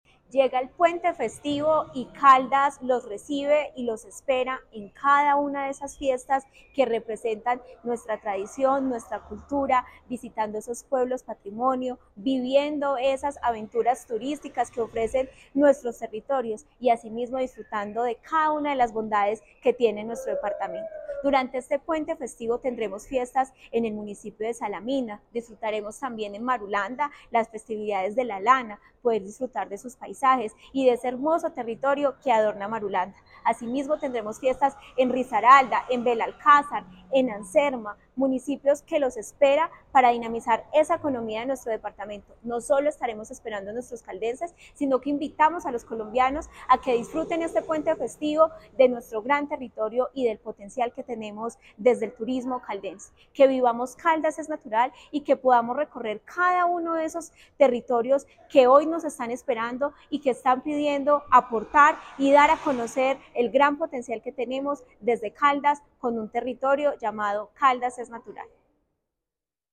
Tania Echeverry, secretaria de Desarrollo, Empleo e Innovación de Caldas.
Tania-Echeverry-Rivera-secretaria-de-Desarrollo-Empleo-e-Innovacion-de-Caldas.mp3